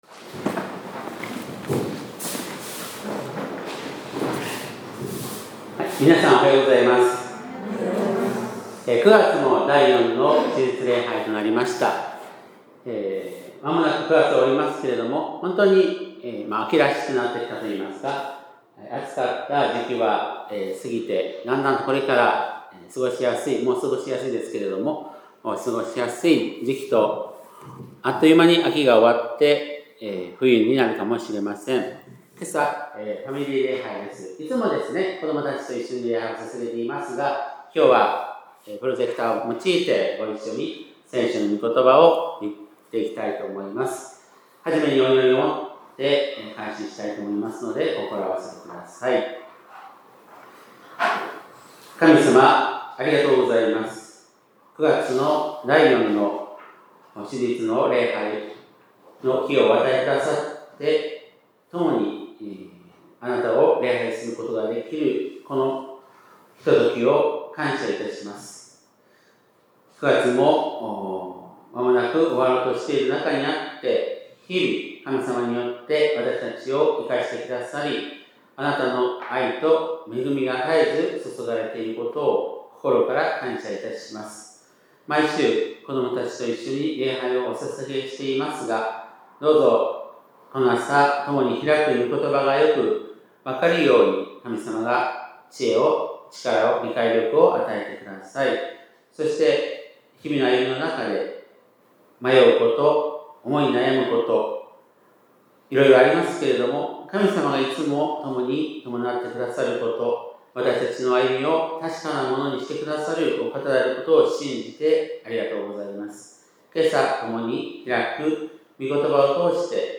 2025年9月28日（日）礼拝メッセージ
本日は、プロジェクターとパワーポイントを用いてのファミリー礼拝でした。